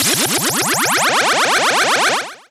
warpon.wav